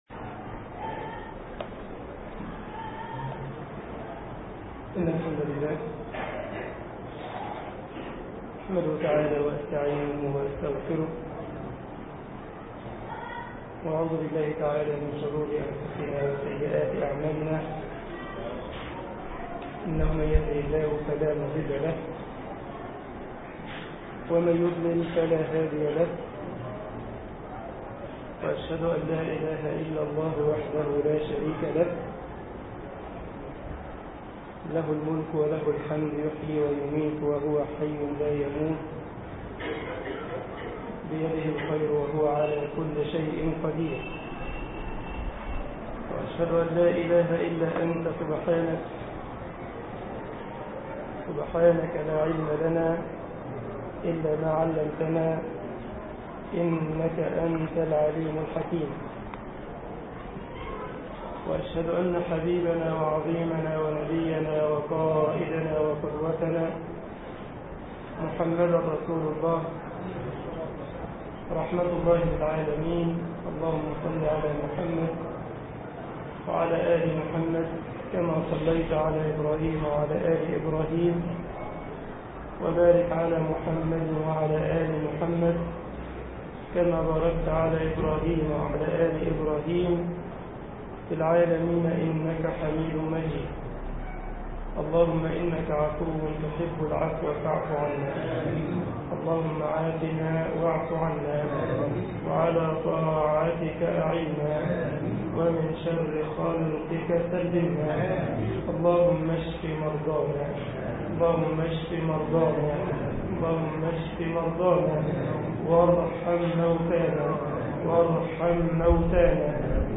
مسجد غمرة المنوفي ـ الشرابية ـ القاهرة